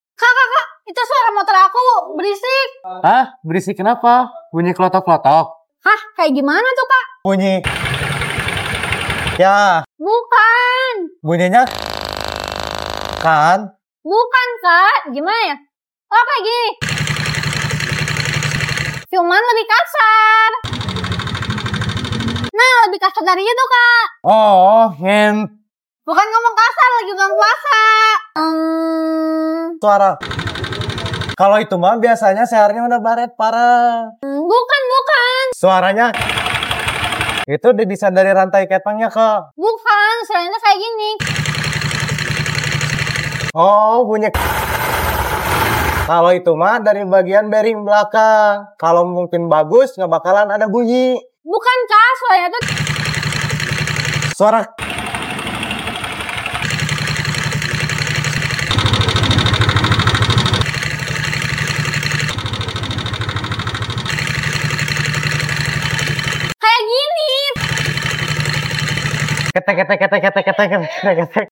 Menurut kalian suara mesinnya kenapa ya?